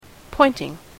/ˈpɔɪntɪŋ(米国英語)/